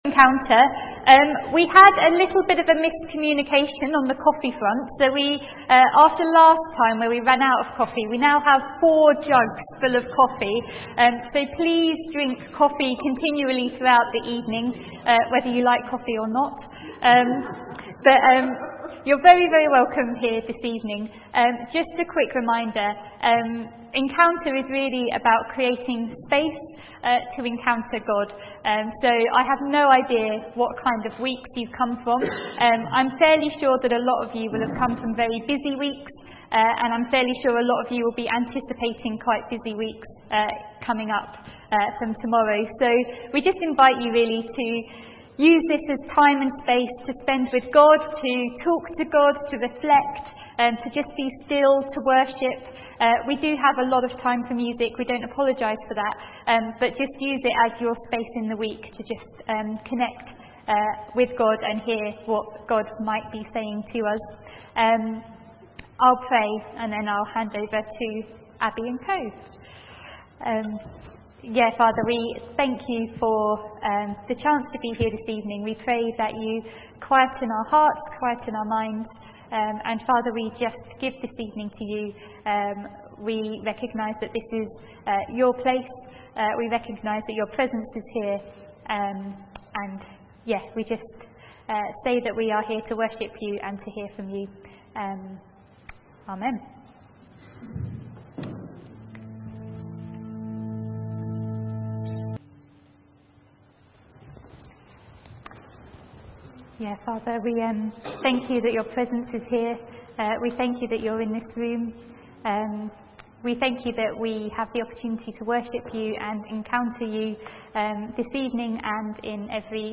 From Service: "6.30pm Service"